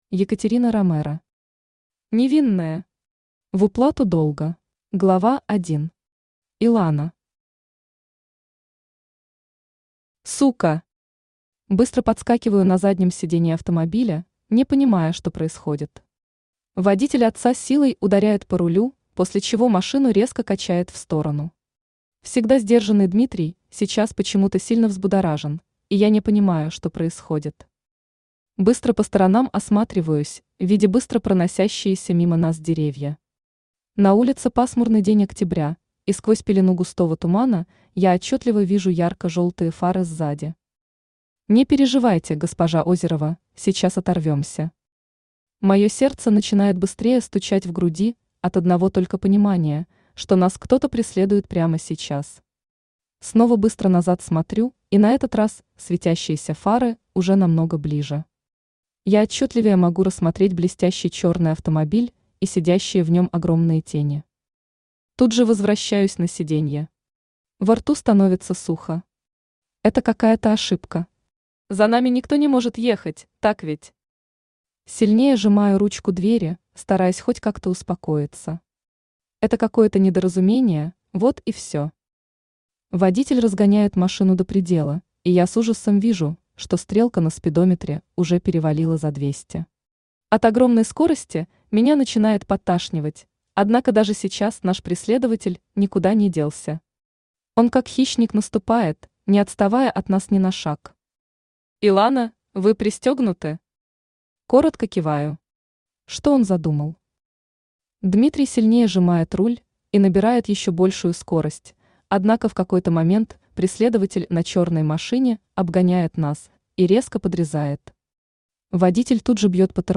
Аудиокнига Невинная. В уплату долга | Библиотека аудиокниг
В уплату долга Автор Екатерина Ромеро Читает аудиокнигу Авточтец ЛитРес.